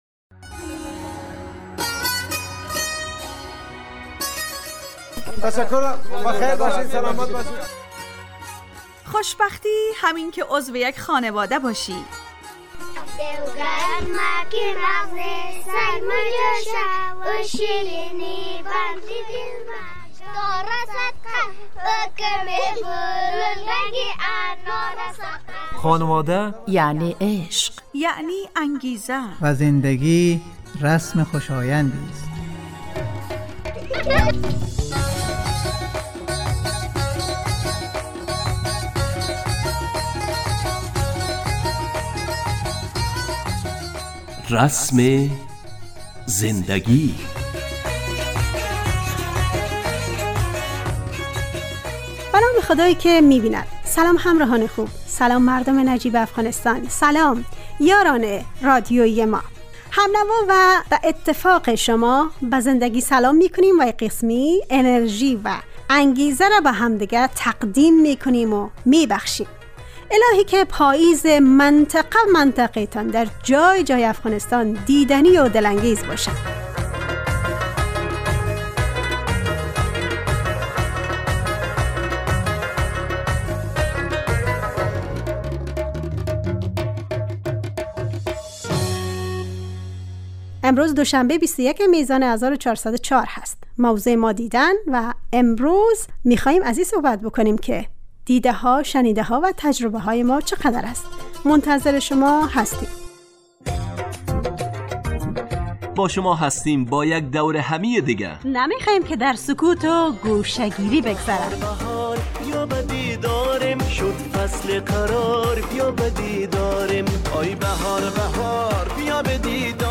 برنامه خانواده رادیو دری